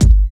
101 KICK.wav